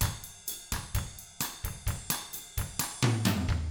129BOSSAF4-L.wav